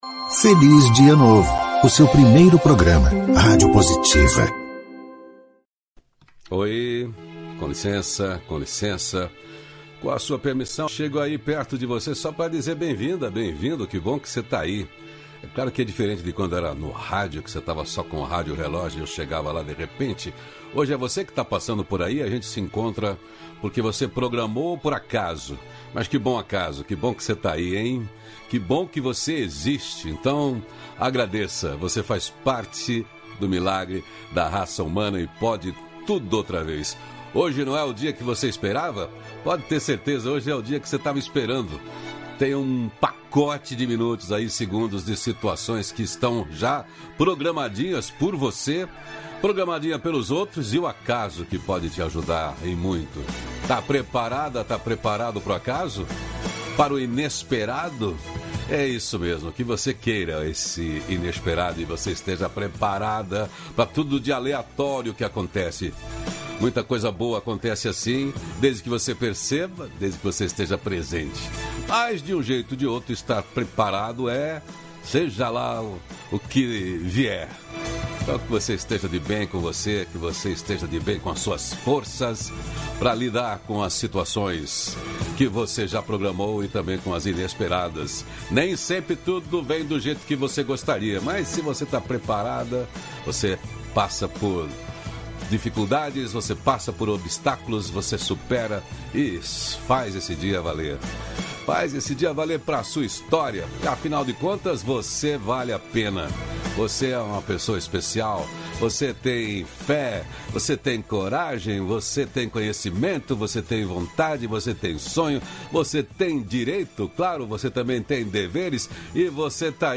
Diálogos Nutritivo